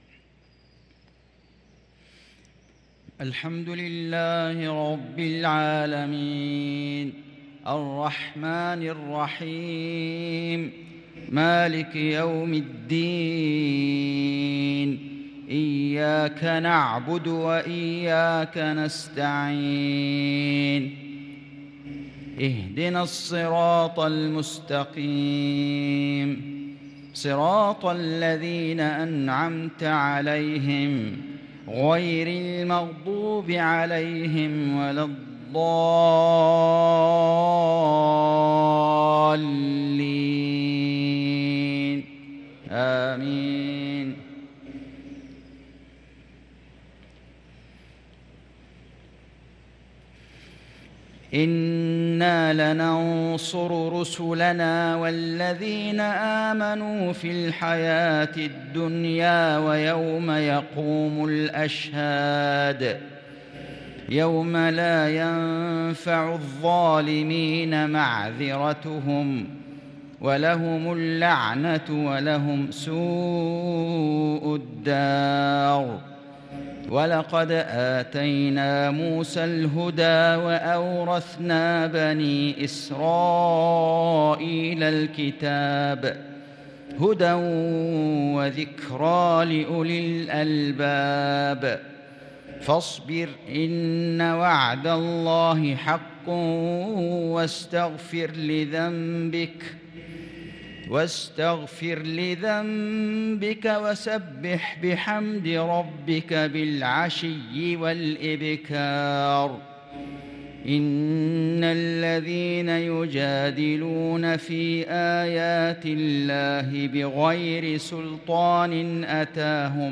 صلاة المغرب للقارئ فيصل غزاوي 2 ربيع الأول 1444 هـ
تِلَاوَات الْحَرَمَيْن .